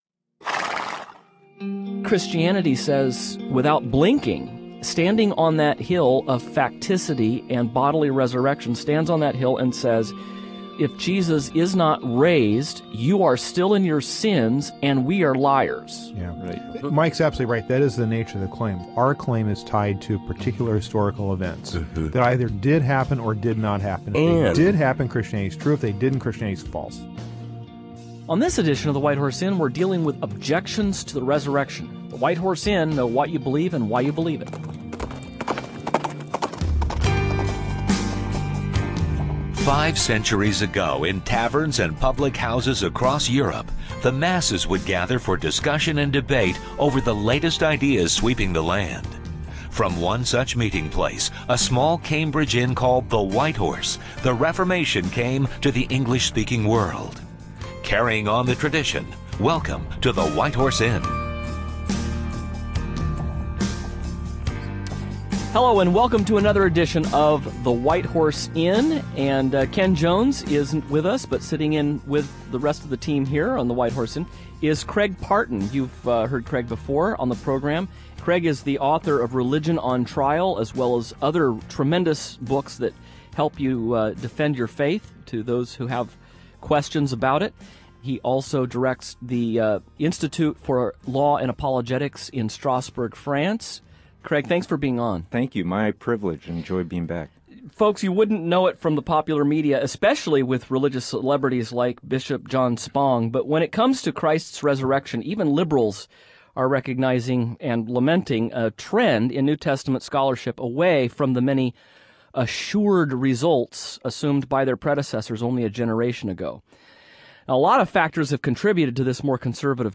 So how are we to answer the person who claims that Jesus never really died on the cross, or that the miracle stories associated with Christ are complete fabrications? The hosts discuss these questions and…